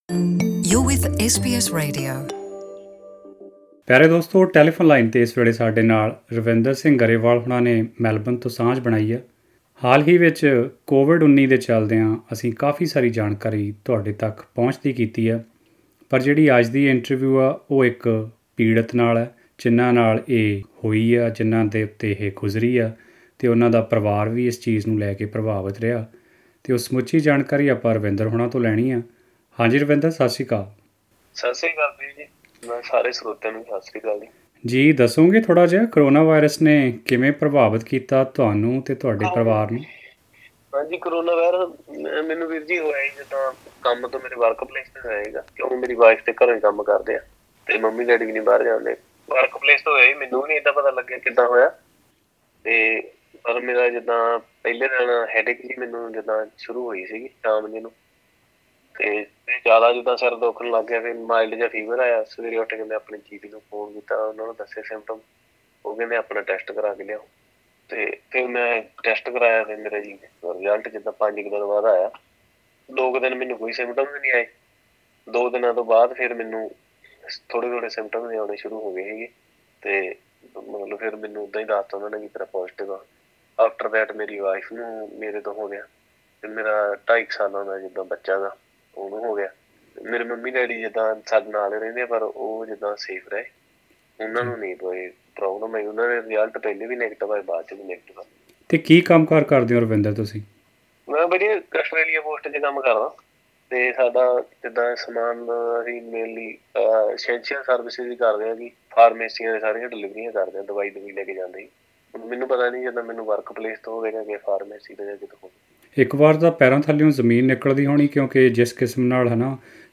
ਪੂਰੀ ਜਾਣਕਾਰੀ ਲਈ ਸੁਣੋ ਇਹ ਗੱਲਬਾਤ...